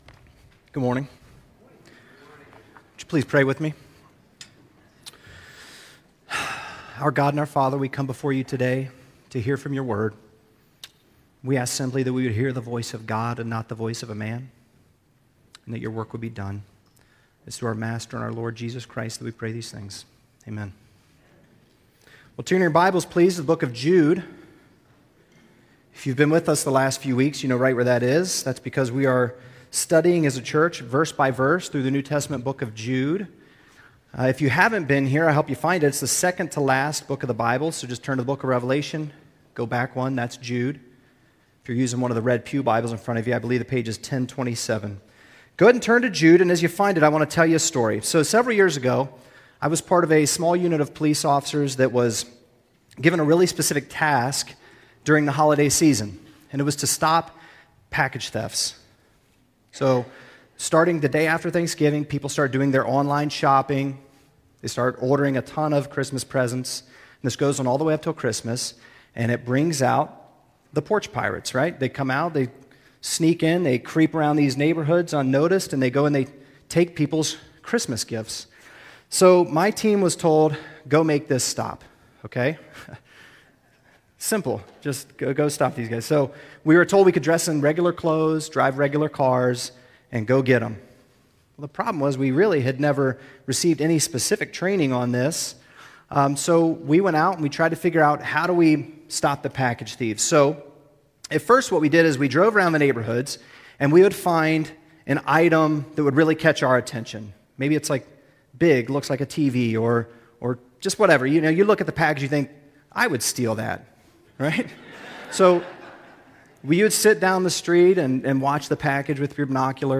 Highland Baptist Church Sermons